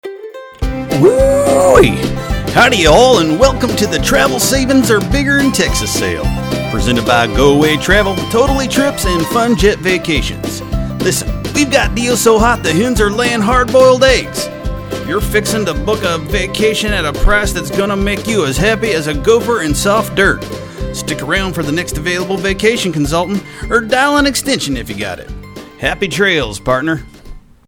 We infused all aspects of the campaign with the Texas flavor. The auto-attendant, menus, and hold music greeted people with the Texas-centric theme.
auto attendant greeting.mp3